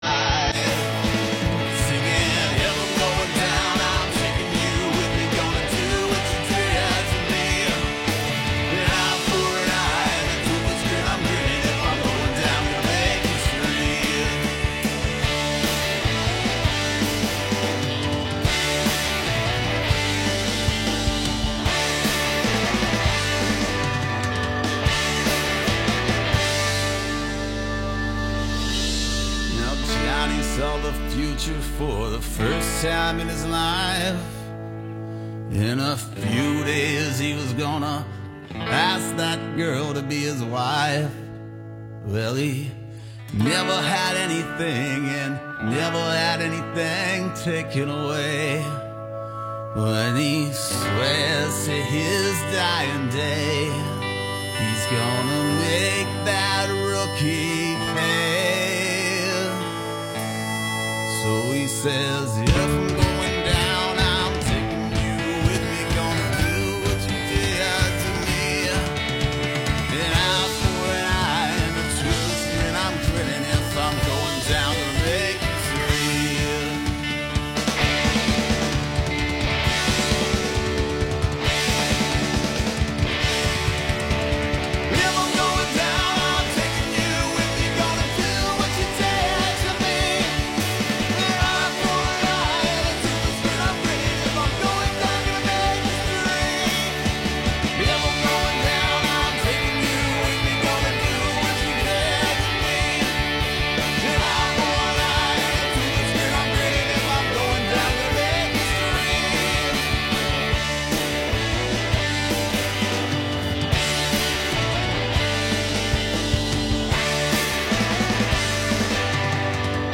They are going to do some their songs stripped down and live in the studio, and we’ll spin their lates release as well.